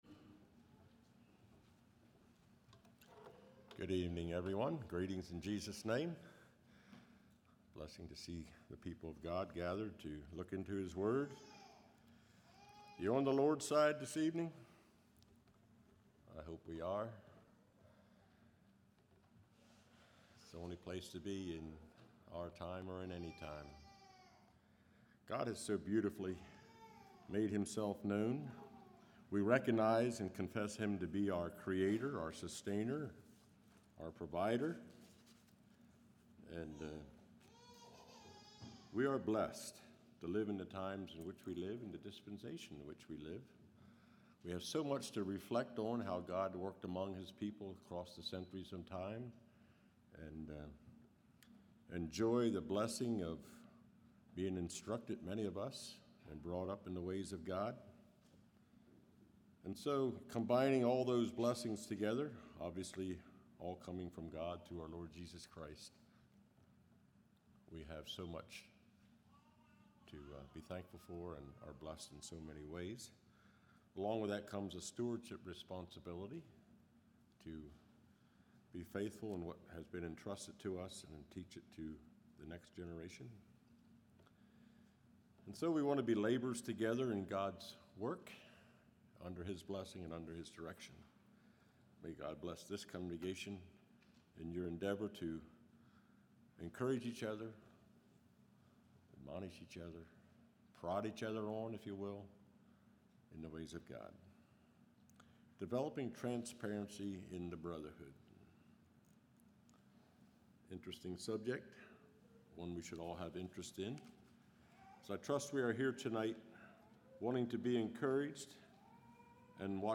Play Now Download to Device Developing Transparency in Brotherhood Congregation: Calvary Speaker